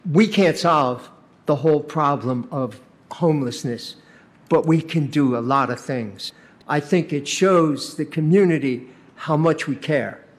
Kalamazoo city commissioners held a public hearing for their 2025 budget, announcing a late amendment to set aside money for the homeless.
Commissioner Don Cooney says it will make sure the most vulnerable are protected for now.